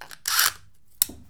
Luckily, I was equipped with a good microphone and a digital tape recorder (it's amazing what you can find in a simple space such as an office).
here to listen to the second found sound